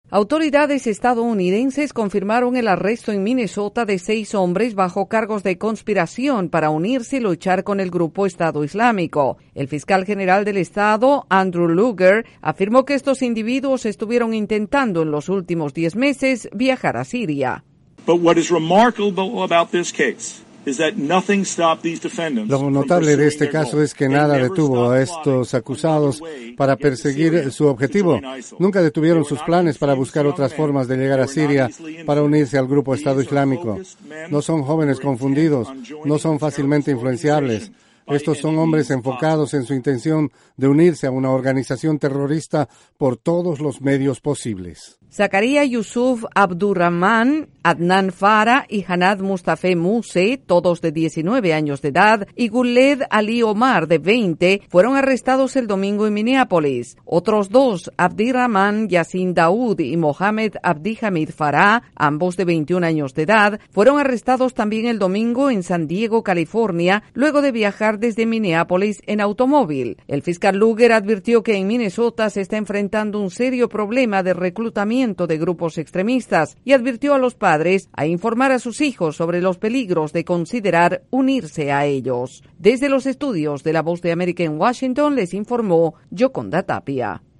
Seis individuos que intentaban viajar a Siria para unirse a los extremistas islámicos fueron detenidos. Desde la Voz de América en Washington DC informa